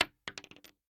pistol_wood_8.ogg